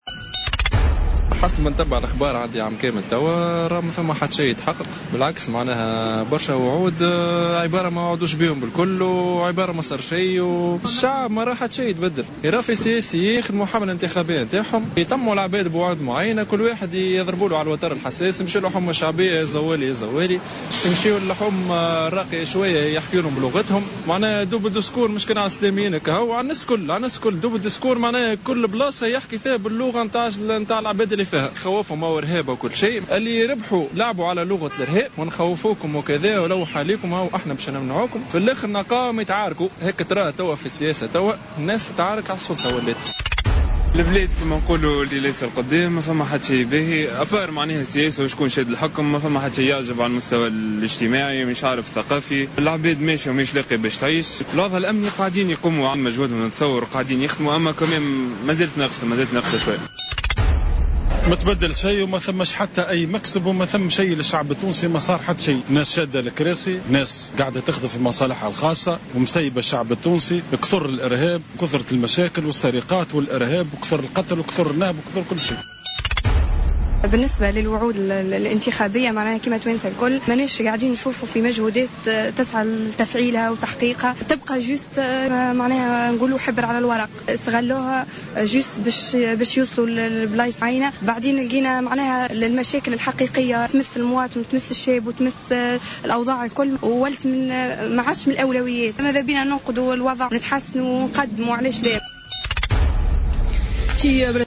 الجوهرة "اف ام" إلتقت بعض المواطنين واستقت مواقفهم بشأن سنة مضت على الإنتخابات وهل تحققت تطلعاتهم حيث أكد عدد من المستجوبين أن السياسيين قاموا بحملاتهم الإنتخابية و قدموا خطابا مزدوجا ووعودا ظلت إلى اليوم حبرا على ورق ومجرد شعارات انتخابية .